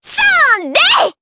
Dosarta-Vox_Attack4_jp.wav